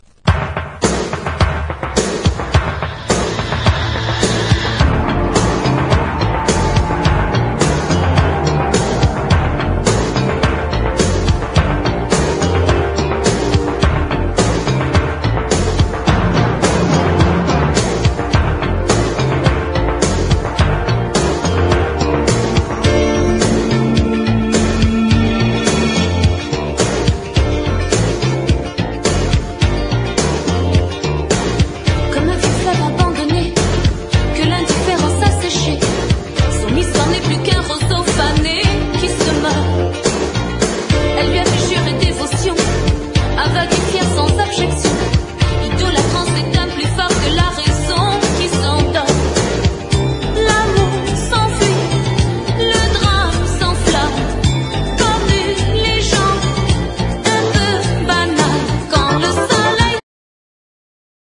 リズム・セクション
EASY LISTENING / JAPANESE JAZZ
シリーズ史上最もグルーヴィーな第三弾！